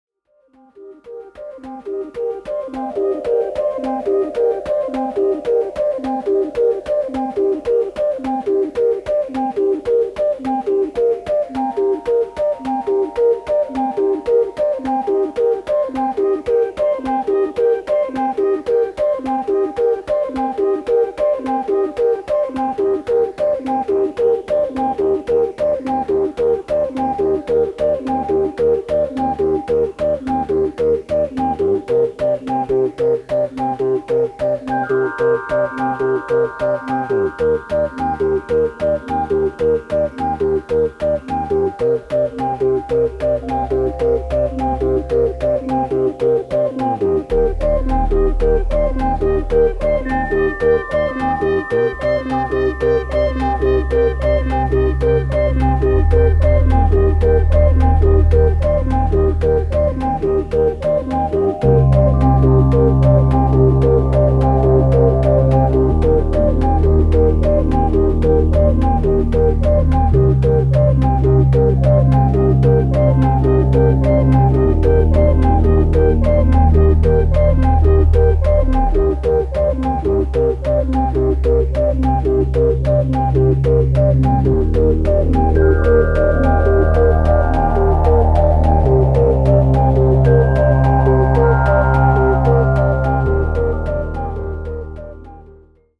with alternating action themes and... more...